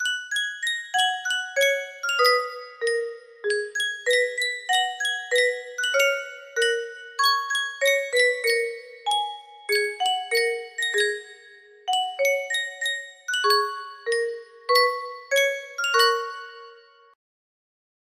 Yunsheng Music Box - Nessun Dorma 2391 music box melody We use cookies to give you the best online experience.
BPM 128